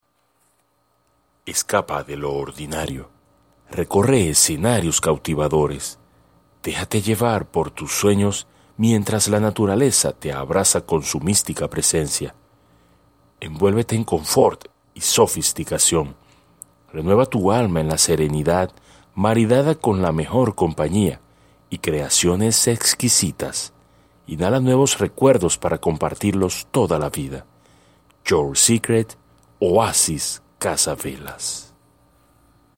Voz fuerte y clara.
Sprechprobe: Werbung (Muttersprache):